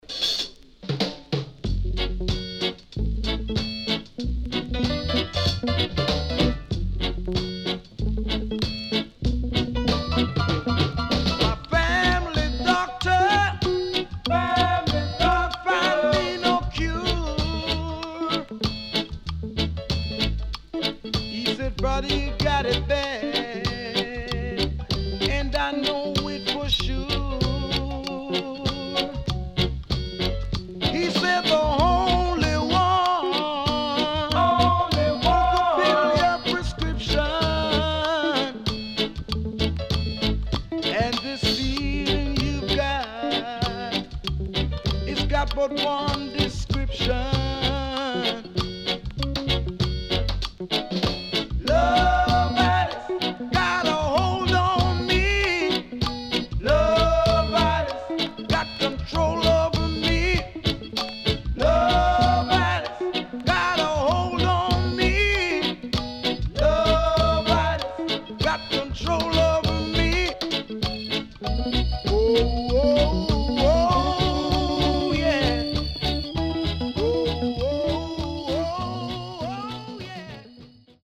HOME > REGGAE / ROOTS
CONDITION SIDE A:VG(OK)〜VG+
Nice Vocal.W-Side Good
SIDE A:少しチリノイズ入りますが良好です。